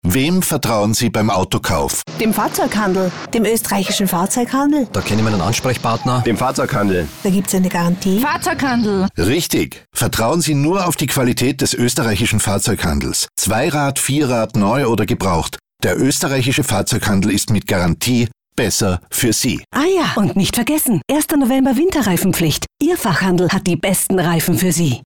Mit diesem Slogan wird ab 13. Oktober im Radio geworben.